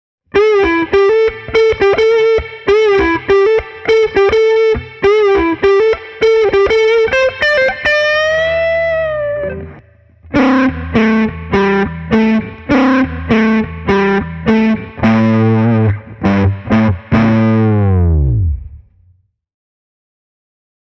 The guitar’s Habanero humbuckers do a fantastic job in getting the Concorde 4-Star’s precise attack and growling mid-range across.
The neck ’bucker displays a fluid, flute-like timbre, without any sign of muddiness: